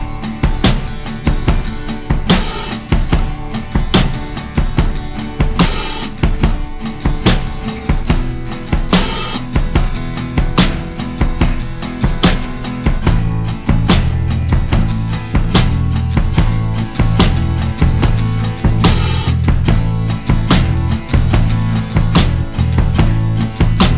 Live In Paris 1976 Pop (1970s) 4:36 Buy £1.50